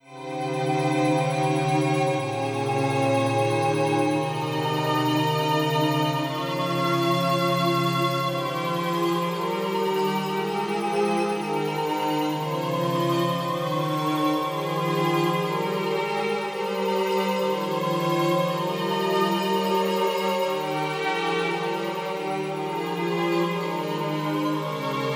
Synth Goblin +Warm